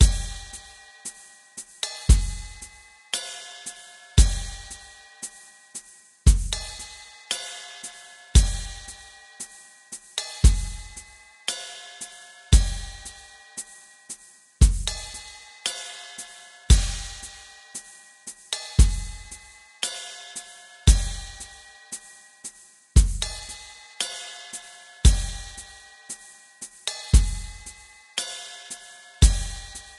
Preview music
trimmed & added fadeout You cannot overwrite this file.